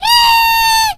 squeak_hurt_vo_02.ogg